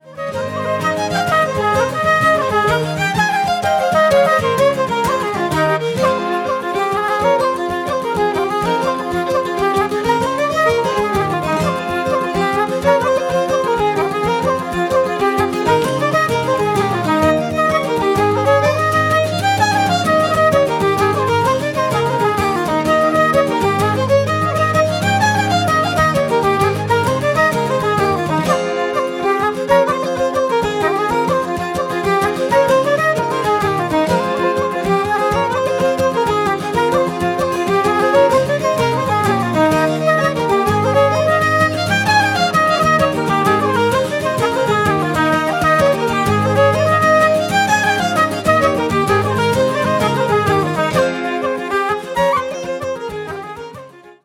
Fiddle
Flute
Guitars